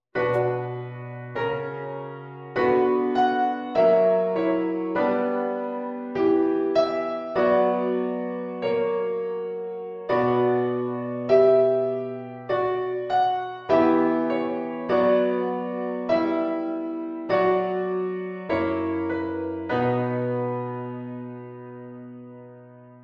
akkoorden die tot stand komen door melodische beweging / prolongatie van een functie